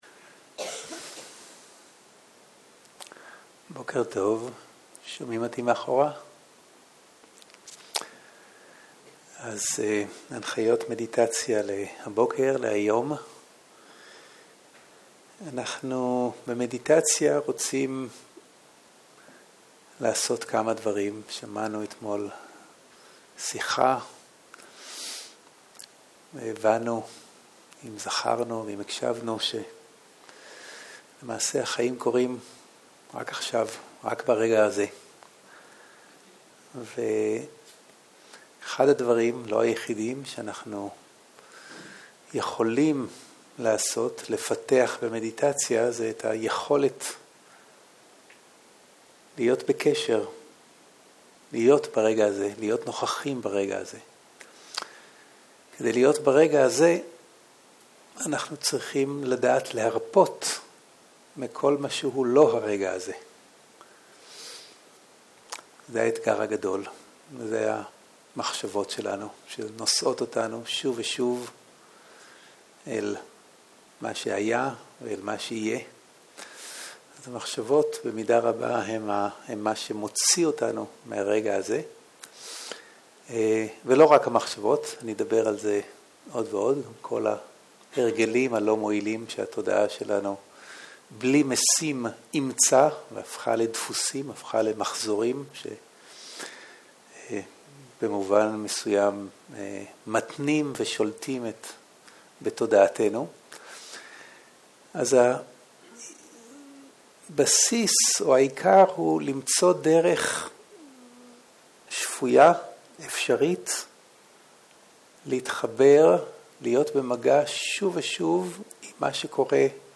בוקר - הנחיות מדיטציה - עבודה עם מחשבות + שאלות ותשובות